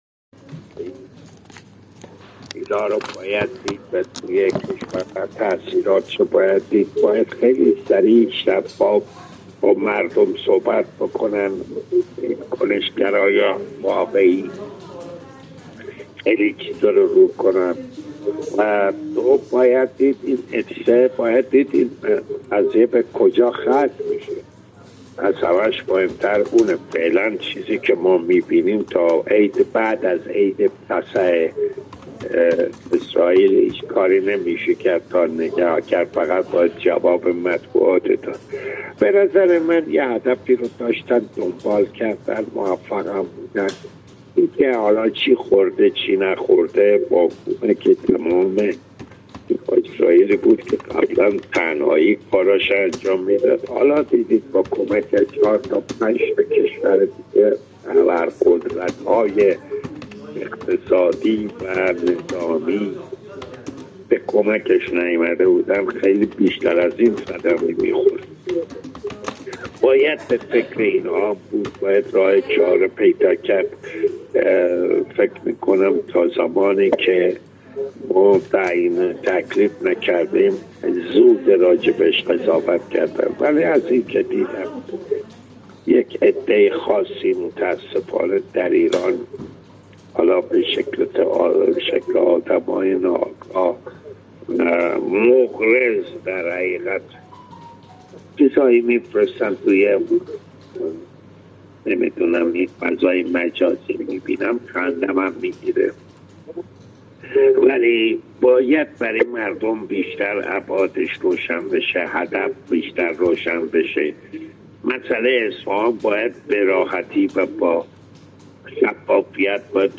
احمد نجفی، بازیگر سینما و تلویزیون در گفت‌وگو با خبرنگار ایکنا بابیان اینکه «وعده صادق» عملیاتی موفق بود، گفت: این رویداد را در امتداد هدفی که برای آن در نظر گرفته‌ شده، اتفاقی موفق محسوب می‌شود.